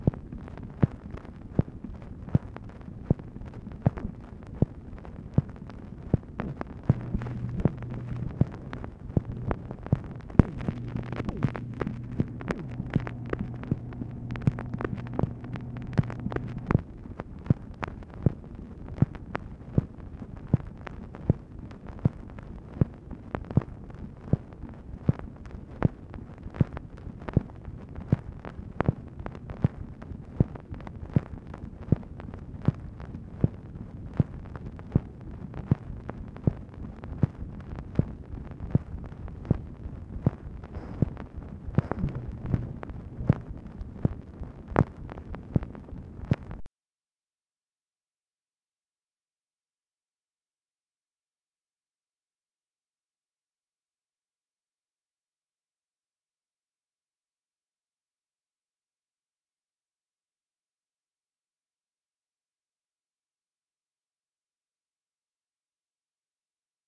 MACHINE NOISE, May 23, 1965